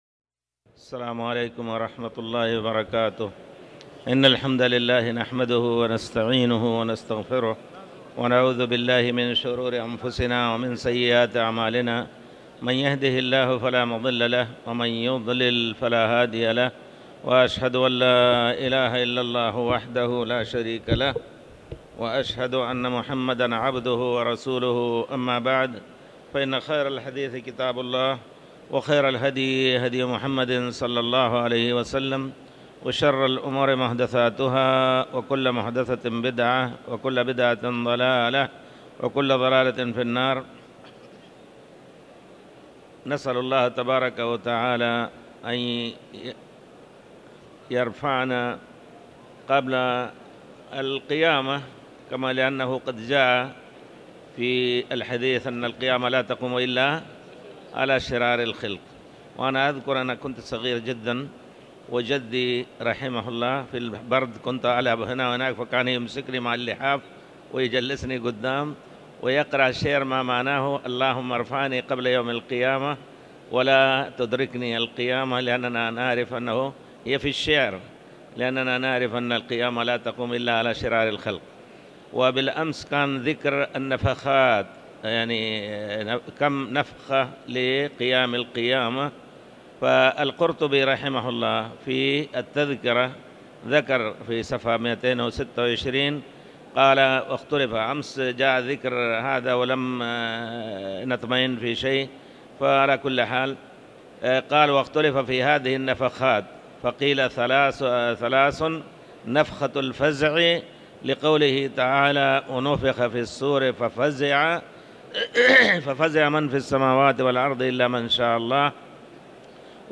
تاريخ النشر ١٣ ربيع الثاني ١٤٤٠ هـ المكان: المسجد الحرام الشيخ